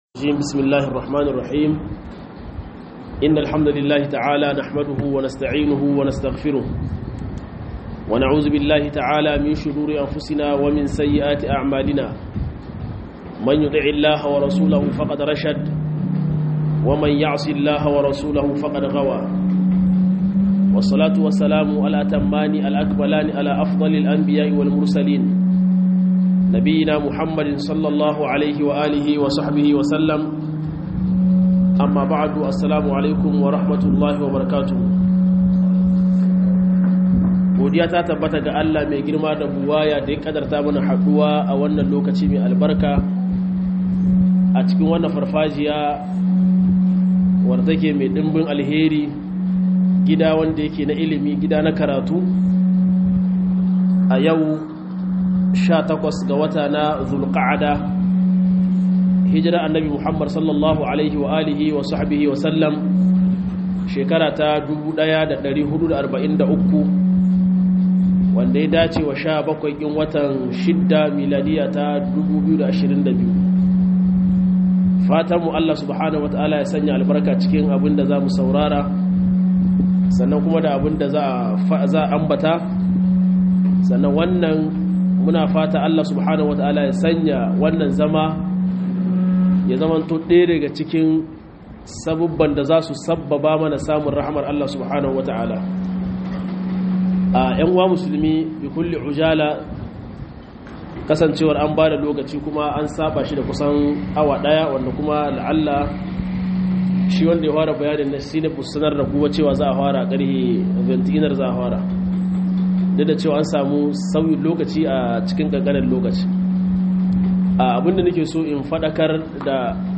ZUWA GAREKI EL JAMI'A - MUHADARA